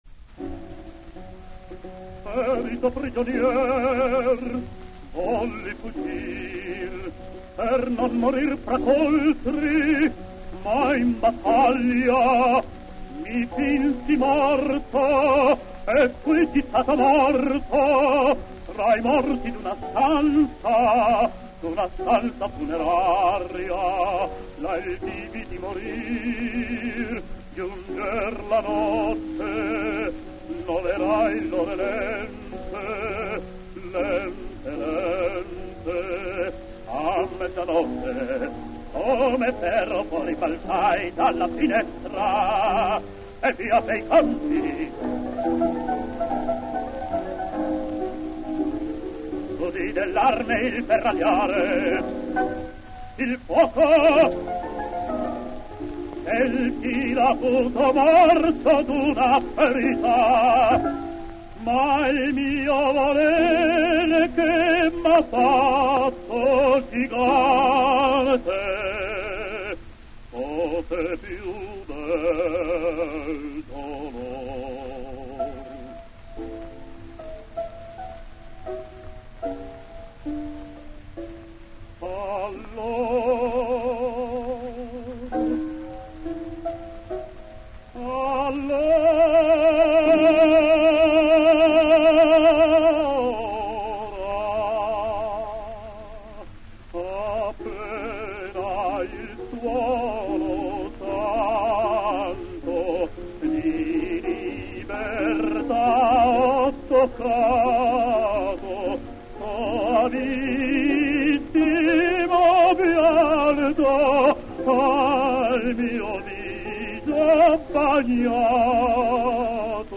Giuseppe de Luca [Bariton]
1907 (mit Klavier)